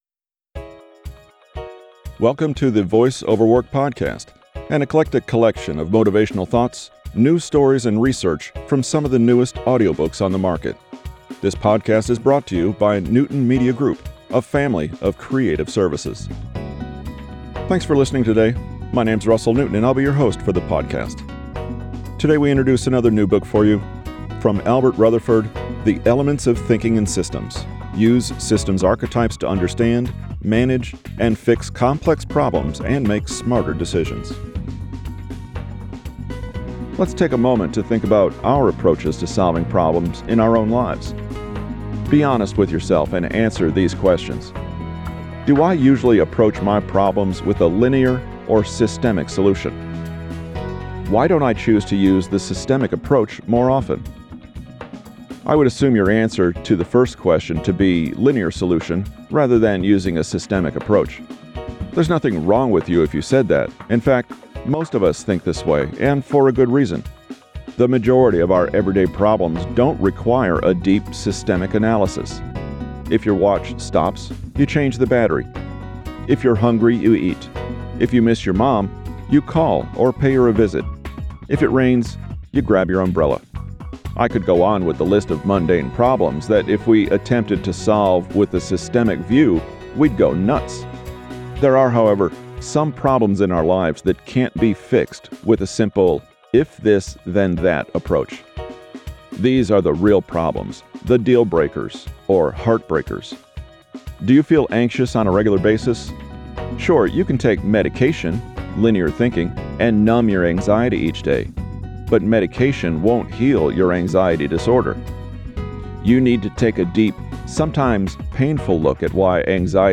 Box Thinking – Inside, Outside, or About – Voice over Work